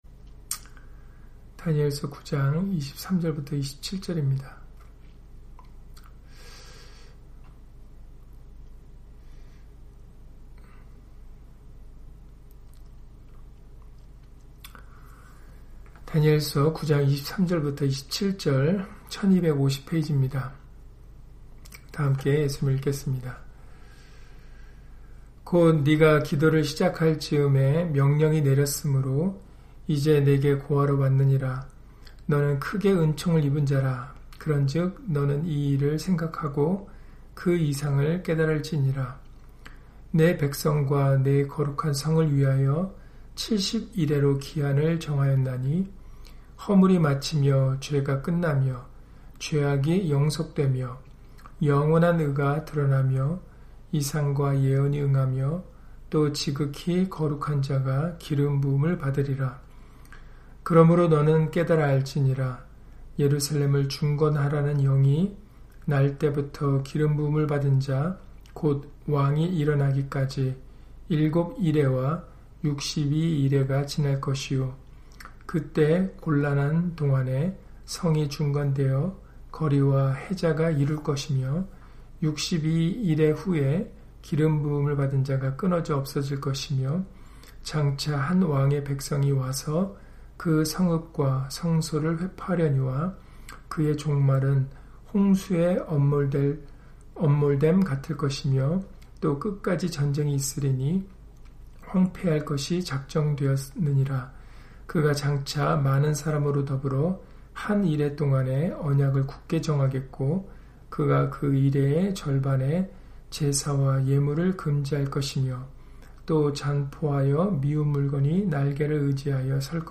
다니엘 9장 23-27절 [기름부음을 받은 자] - 주일/수요예배 설교 - 주 예수 그리스도 이름 예배당